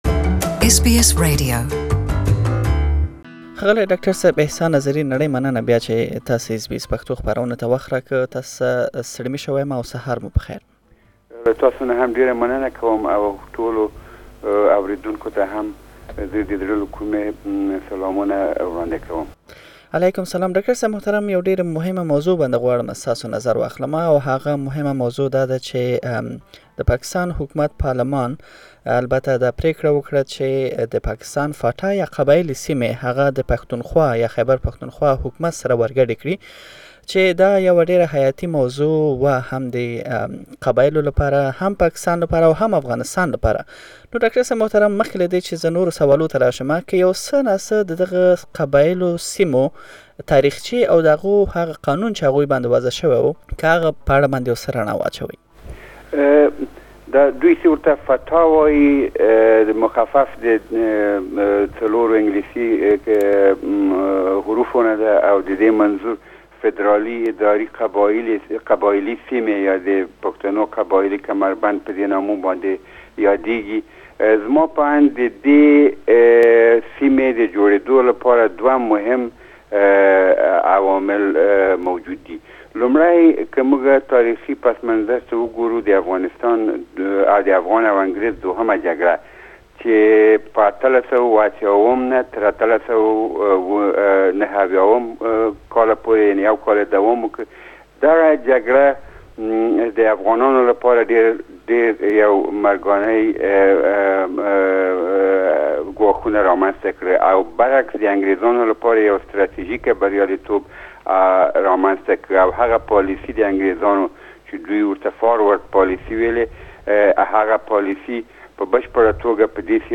Please listen to the first part of the interview Share